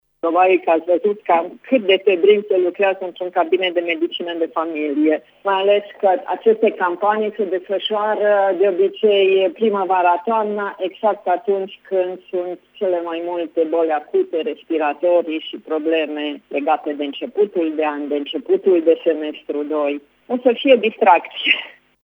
Revine medicul de familie din Tîrgu-Mureş